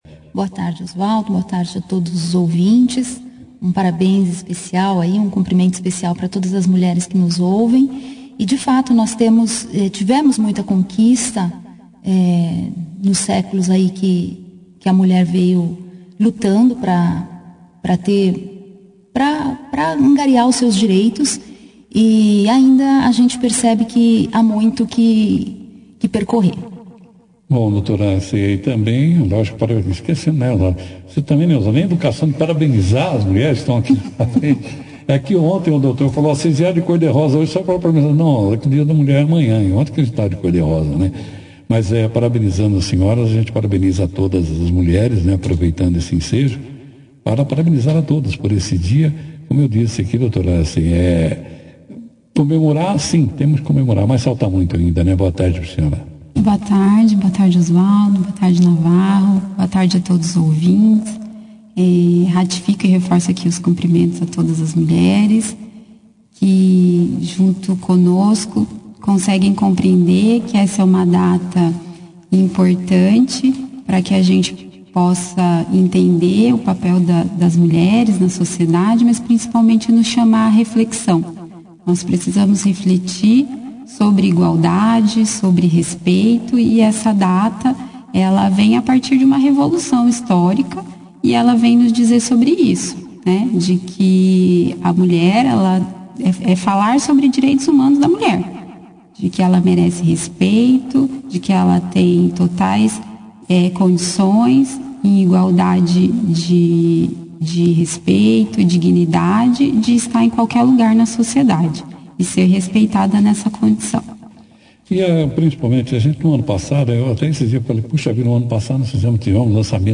Neste dia 8 de março, data em que é celebrado o Dia Internacional da Mulher, o “Operação Cidade” recebeu como convidadas a Juíza da Vara Criminal da Comarca de Bandeirantes, Dra. Fabiana Januário Pesseghini, e a promotora de Justiça, Dra. Aracê Razaboni Teixeira, para uma entrevista sobre as comemorações deste dia.